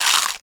horror
Flesh Bite Crunch 3